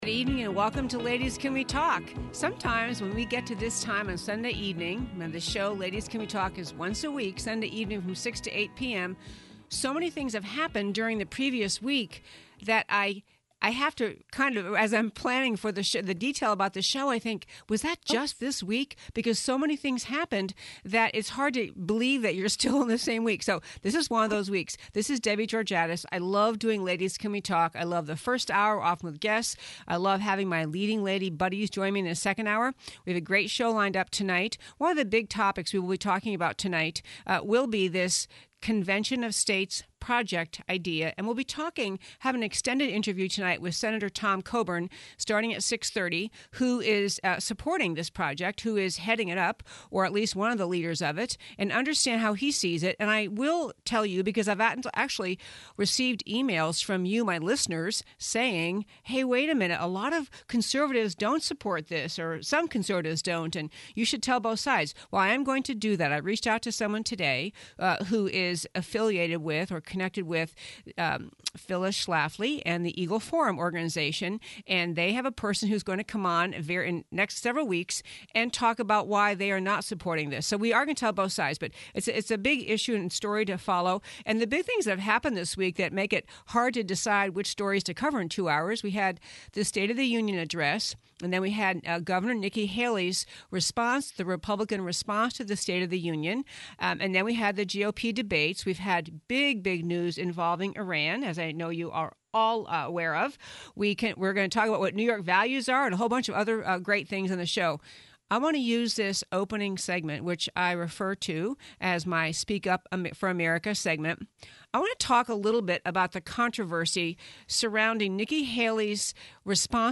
Listen to the first hour of our January 17th show, here.